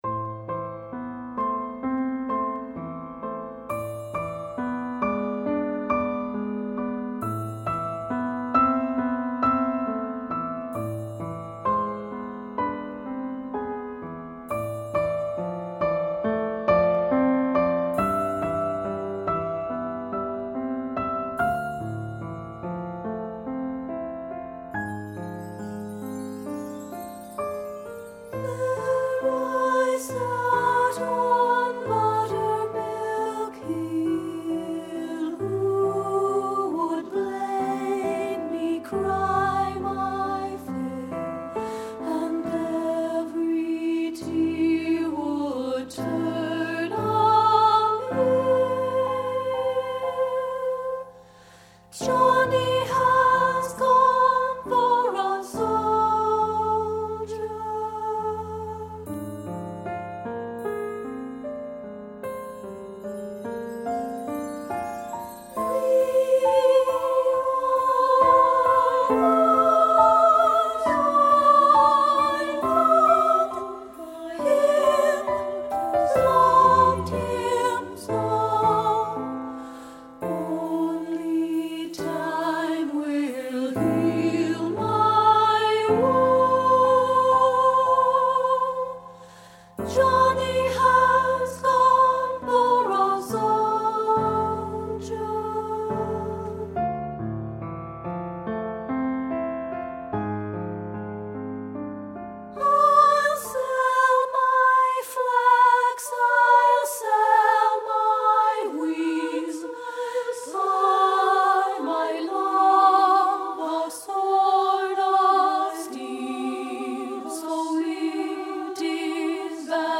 Composer: Civil War Ballad
Voicing: SSA